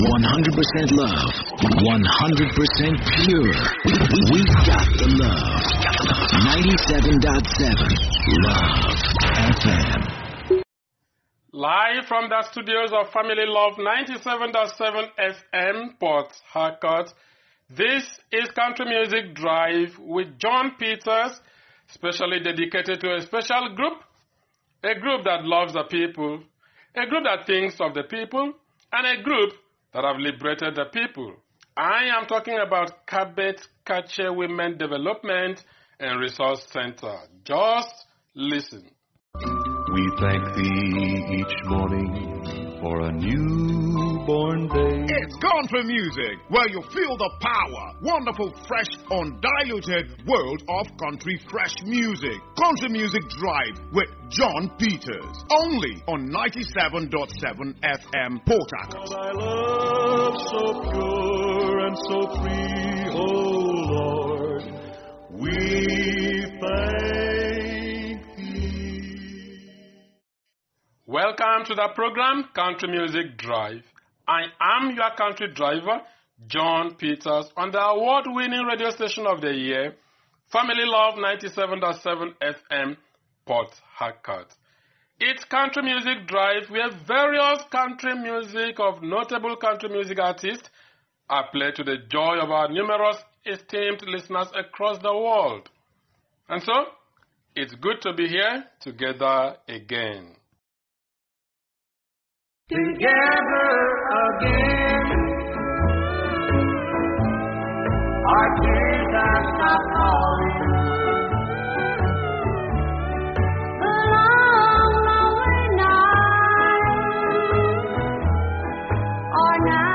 Creative report: newspaper article and radio item